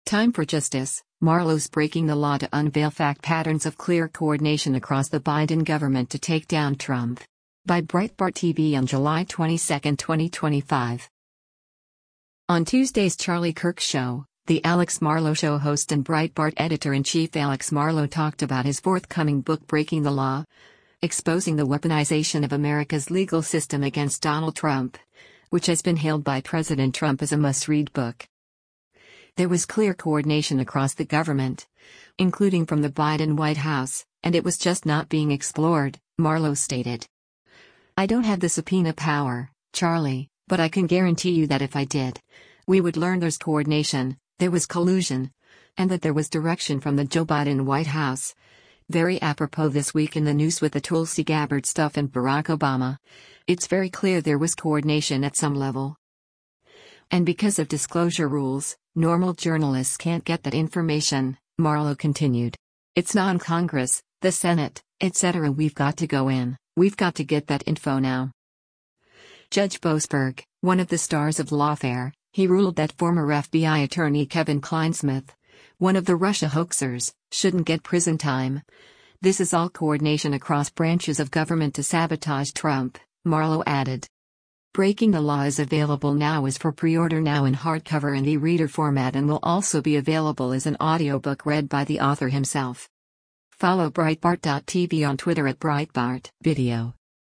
On Tuesday’s Charlie Kirk ShowThe Alex Marlow Show host and Breitbart Editor-in-Chief Alex Marlow talked about his forthcoming book Breaking the Law: Exposing the Weaponization of America’s Legal System Against Donald Trump, which has been hailed by President Trump as a “must read” book.